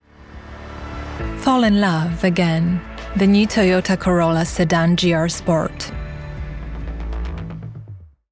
RODE NT1A, cabine vocale entièrement isolée dans un placard. Interface Scarlett 2i2, MacBook Air, Adobe Audition
Mezzo-soprano